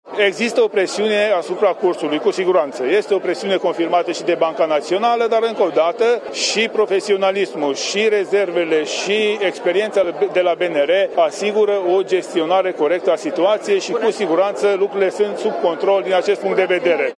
Ministrul Finanțelor, Tanczos Barna: „Este o presiune confirmată și de Banca Națională, dar profesionalismul, rezervele și experiența de la BNR asigură o gestionare corectă”